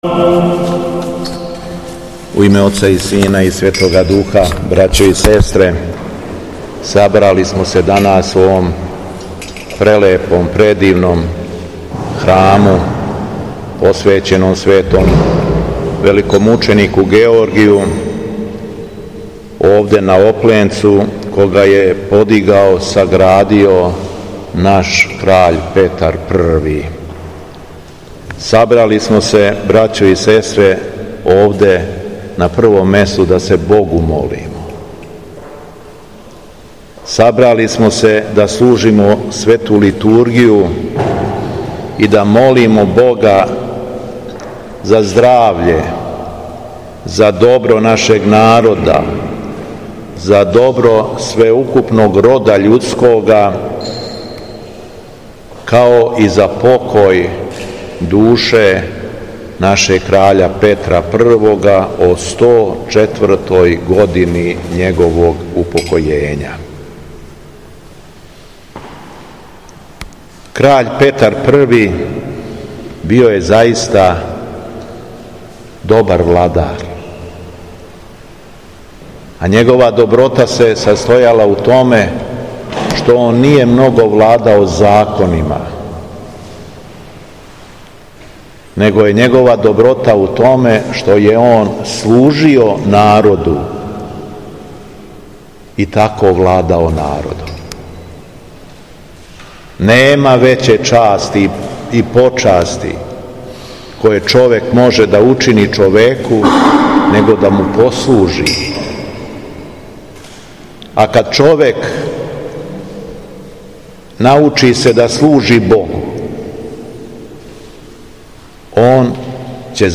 Поводом 104 године од смрти Краља Петра I Карађорђевића у храму Светог Великомученика Георгија на Опленцу, 16. августа 2025. године, Његово Високопреосвештенство Архиепископ крагујевачки и Митрополит шумадијски Господин Јован служио је Свету архијерејску Литургију и парастос Краљу Петру I Карађорђев...
Беседа Његовог Високопреосвештенства Митрополита шумадијског г. Јована
Након прочитаног зачала из Светог Јеванђеља Високопреосвећени Митрополит се обратио верном народу рекавши: